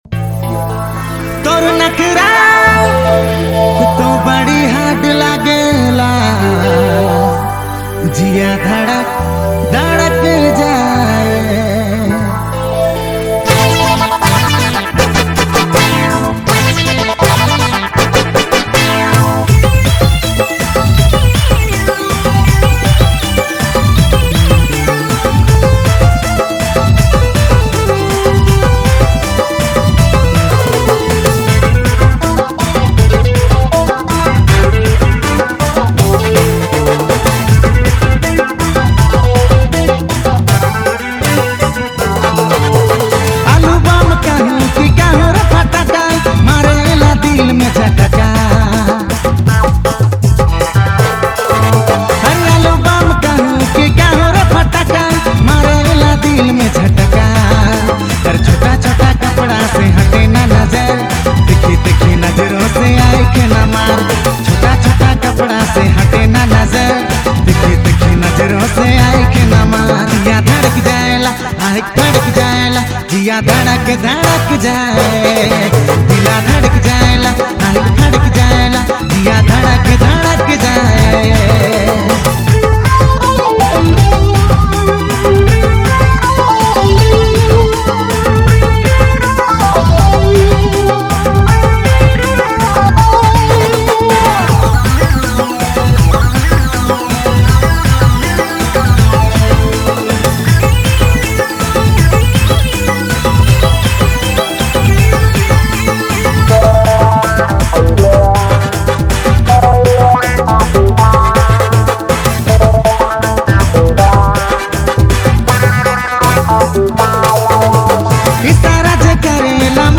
Experience the vibrant beats
the trending Nagpuri video song of 2021.